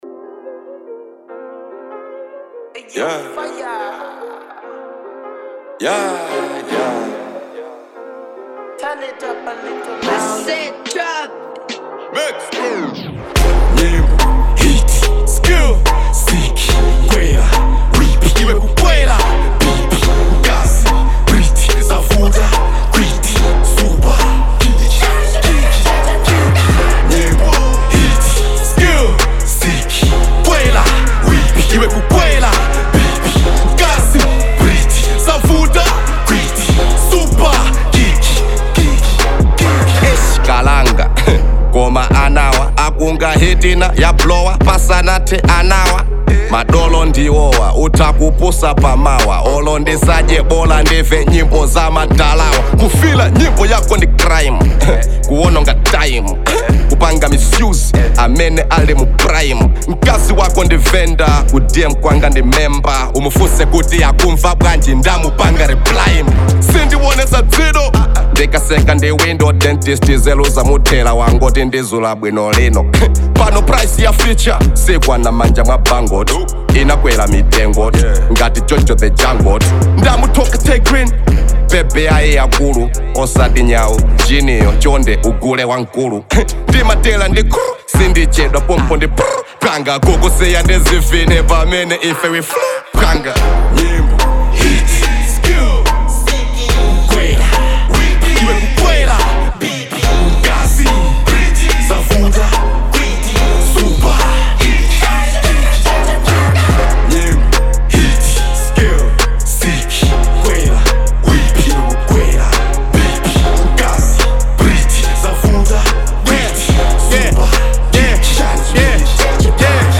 Hiphop song